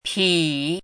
chinese-voice - 汉字语音库
pi3.mp3